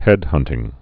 (hĕdhŭntĭng)